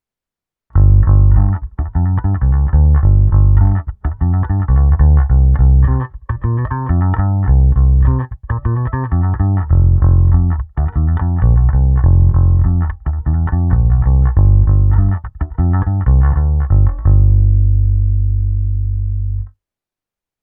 Zvukově je to klasický Precision, i s hlazenkami hraje naprosto parádně, a oproti mnoha jiným Precisionům má tenhle opravdu pevné, vrčivé Éčko.
Stejné kolečko jako ukázka 2, ale už s kompresorem, ekvalizací a simulací aparátu:
Ukázka 5 -  simulace předzesilovače Avalon a boxu Science 2×15"